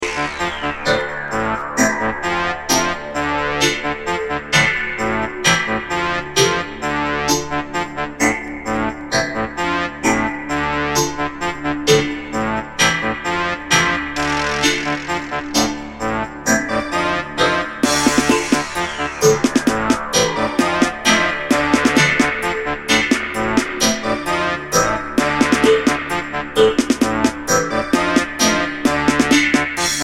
Reggae Ska Dancehall Roots